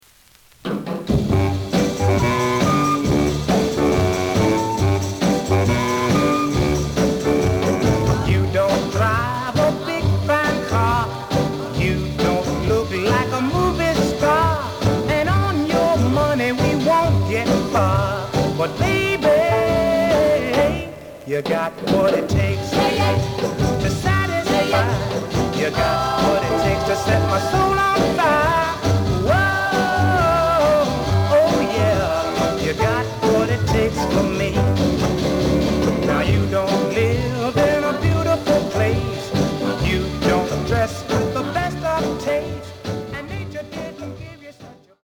The audio sample is recorded from the actual item.
●Genre: Rhythm And Blues / Rock 'n' Roll
●Record Grading: VG (傷は多いが、プレイはまずまず。Plays good.)